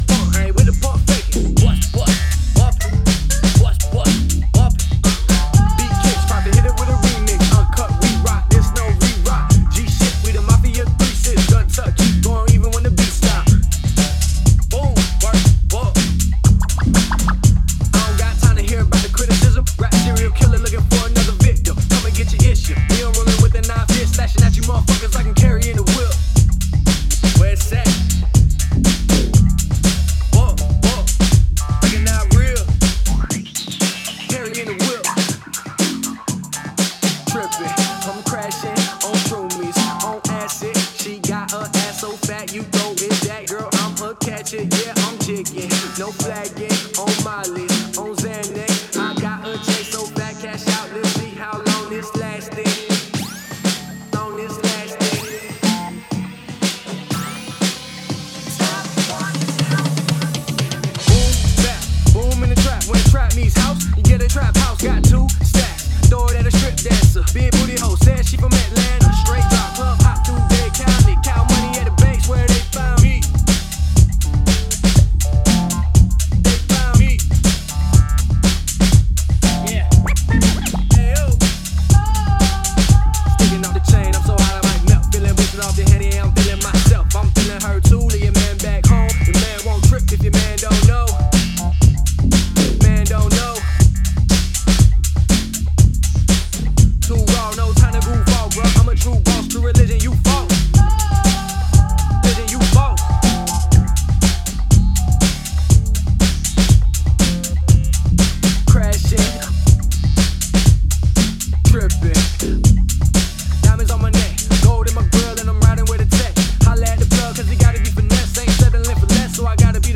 broken take on minimal